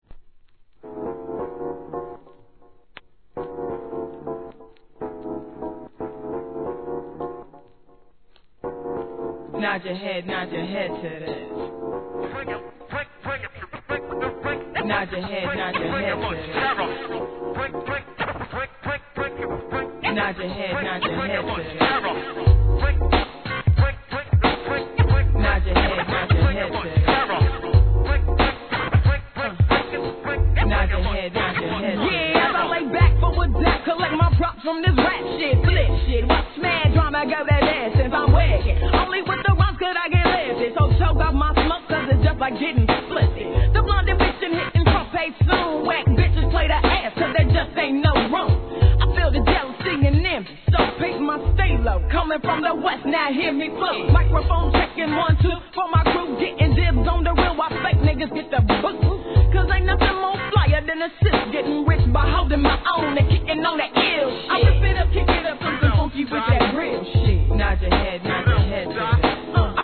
HIP HOP/R&B
使いの激ﾔﾊﾞﾒﾛｳﾁｭｰﾝ!